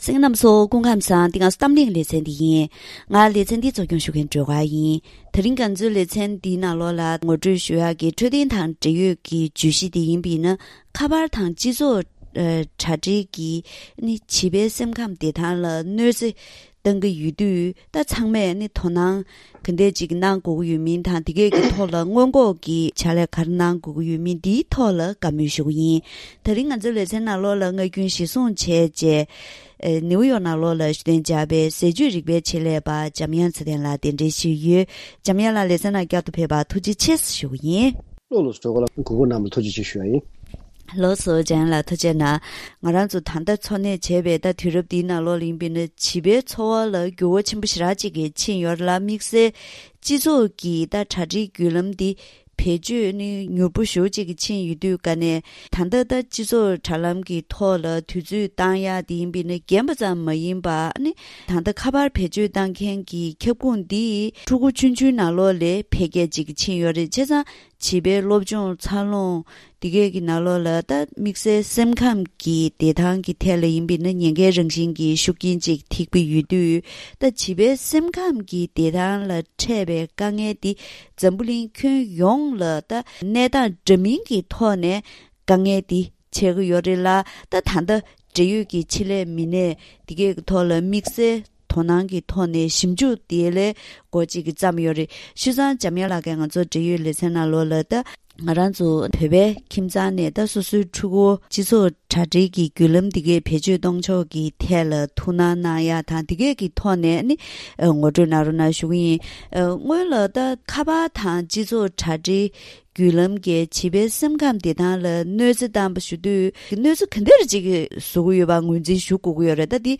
ད་རིང་གི་གཏམ་གླེང་ལེ་ཚན་ནང་ཁ་པར་དང་སྤྱི་ཚོགས་དྲ་རྒྱས་བྱིས་པའི་སེམས་ཁམས་བདེ་ཐང་ལ་གནོད་འཚེ་གཏོང་གི་ཡོད་པས། ཚང་མས་དོ་སྣང་གང་འདྲ་དགོས་མིན་དང་སྔོན་འགོག དེ་བཞིན་བྱིས་པའི་འཕྲོད་བསྟེན་དང་འབྲེལ་བའི་སྐོར་ལ་བཀའ་མོལ་ཞུས་པ་ཞིག་གསན་རོགས་གནང་།